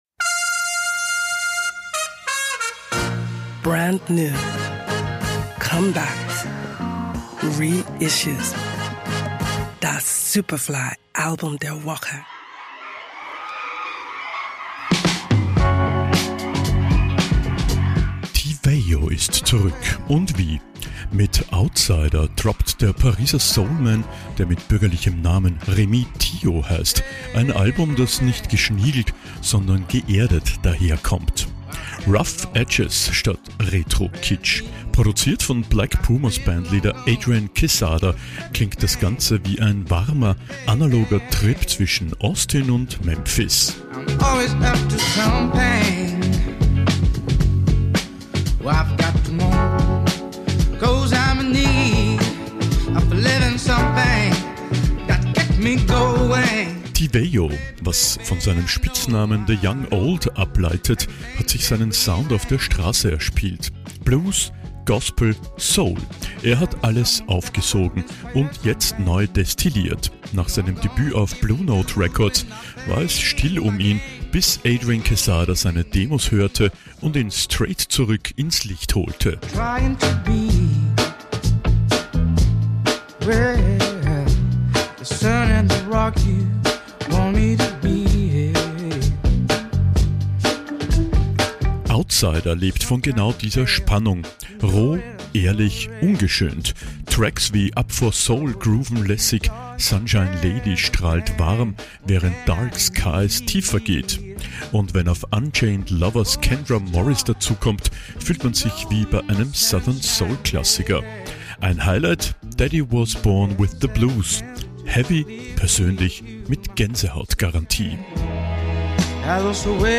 Soulman
Rough edges statt Retro-Kitsch.